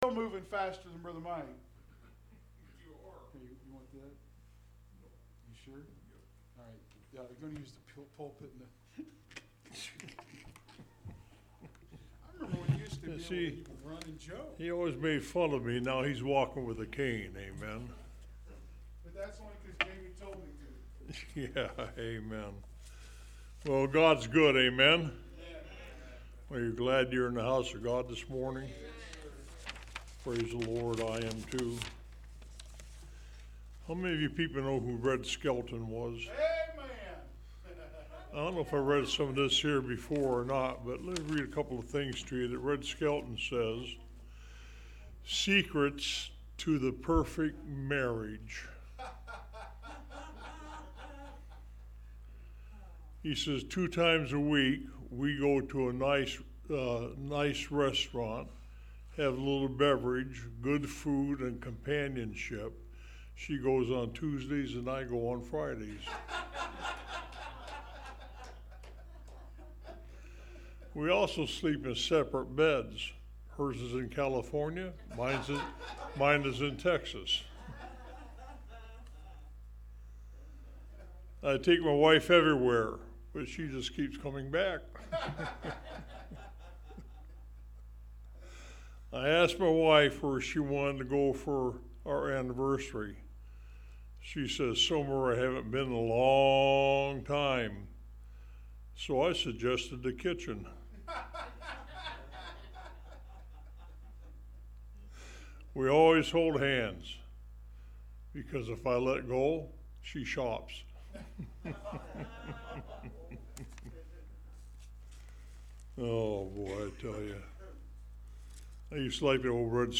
From Series: "Sunday School"